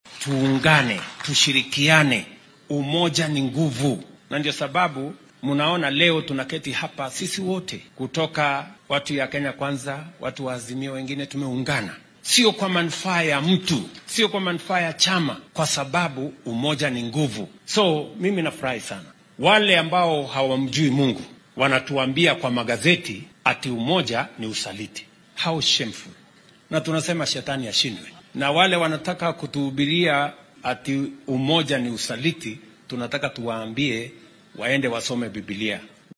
Hoggaamiyaha dalka Willaim Ruto ayaa xilli uu maanta ku sugnaa ismaamulka Narok waxaa uu difaacay maamulka dowladeed ee la ballaariyay ee uu ku soo daray xubno ka tirsan garabka mucaaradka.